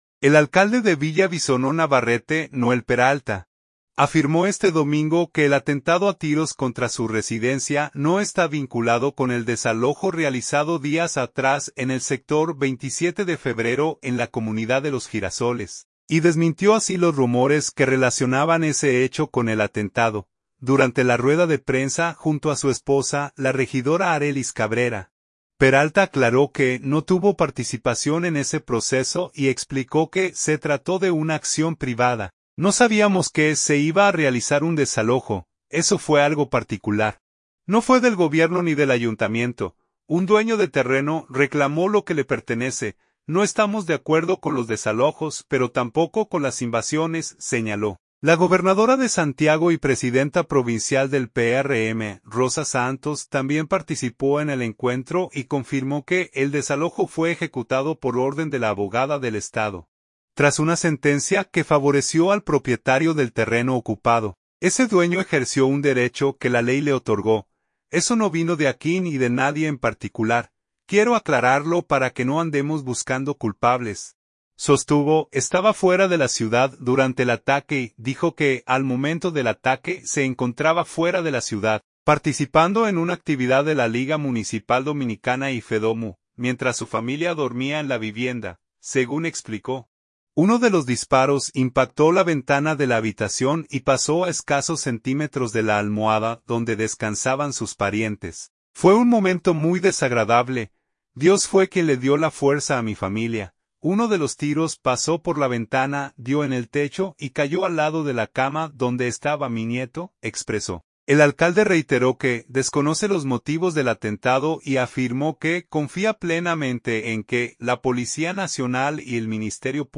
Durante la rueda de prensa junto a su esposa, la regidora Arelys Cabrera; Peralta aclaró que no tuvo participación en ese proceso y explicó que se trató de una acción privada.